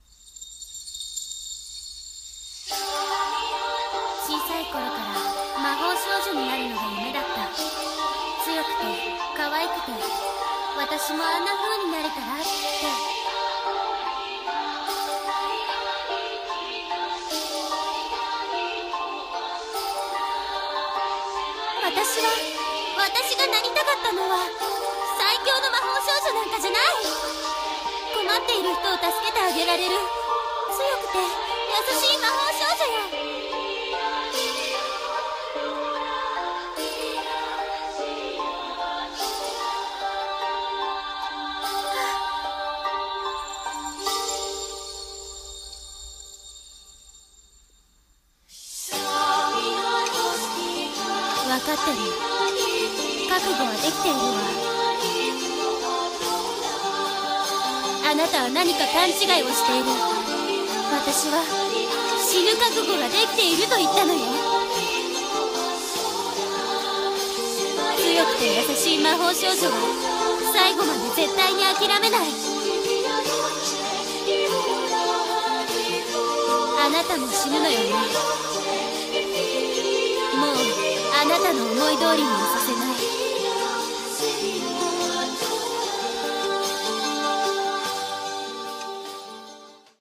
声劇台本【魔法少女の最期の戦い】